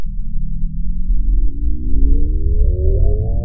activate.wav